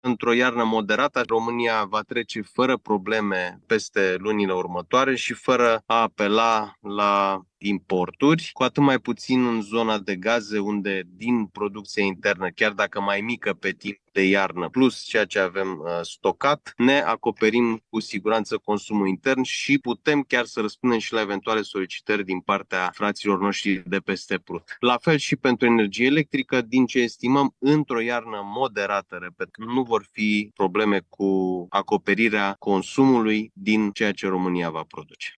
Ministrul Energiei Sebastian Burduja spune că România stă mai bine decât oricând în ceea ce privește stocurile de gaze și energie și că nu vom avea probleme în această iarnă